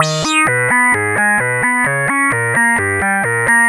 Neo Classical Eb 130.wav